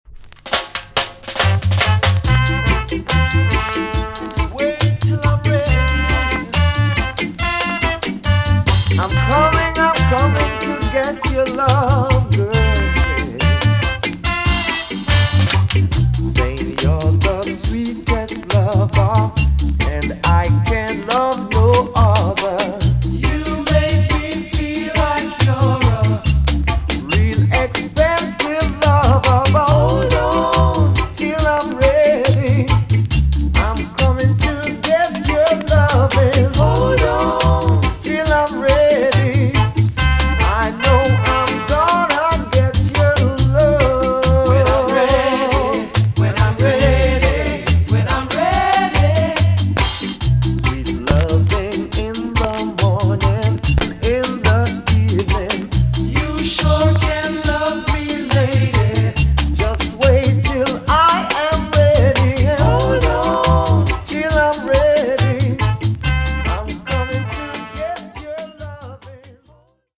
TOP >SKA & ROCKSTEADY
VG+ 少し軽いチリノイズが入りますが良好です。